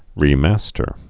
(rē-măstər)